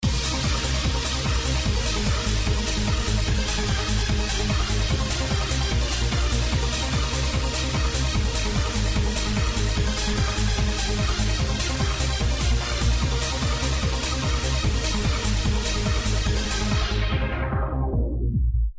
Question 147bpm Euphoric Trance Track (2003-04)